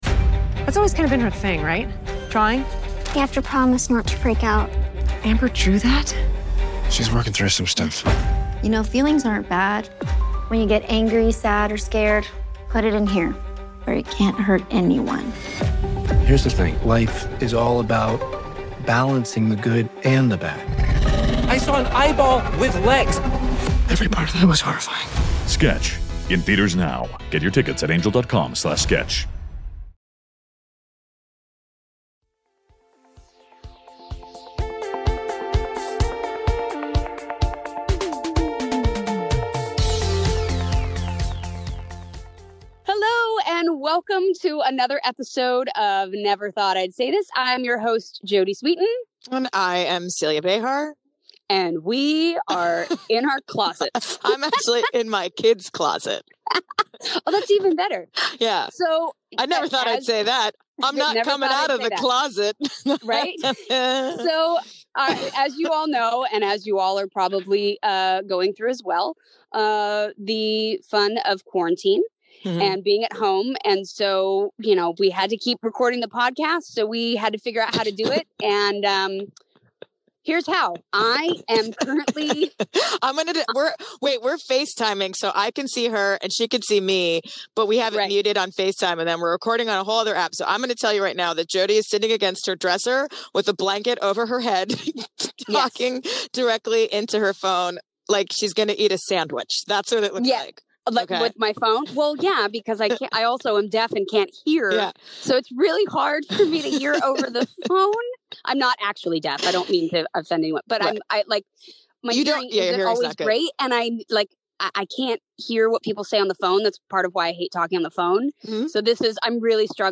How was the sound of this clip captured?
are in full quarantine mode and are dispatching today from their respective closets, because the show must go on!